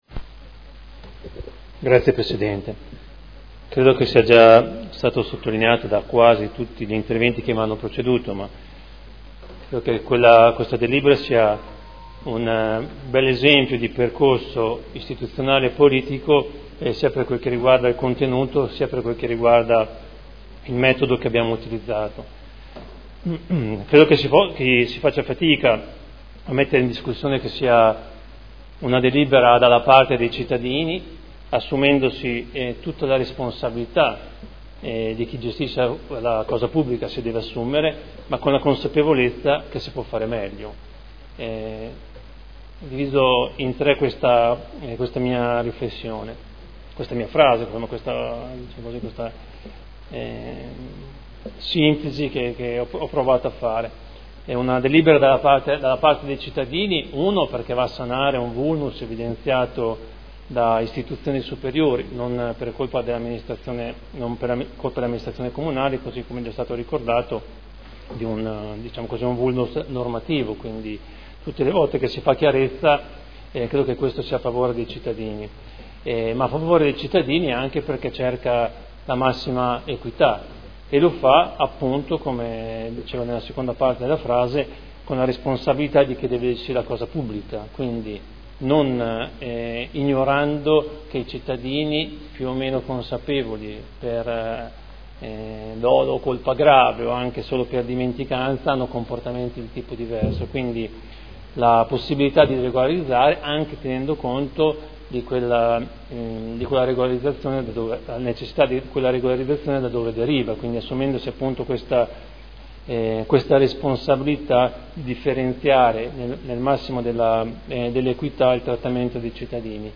Seduta del 09/07/2015 Dibattito. Approvazione del Regolamento per l’utilizzo delle aree di parcheggio a pagamento su strada.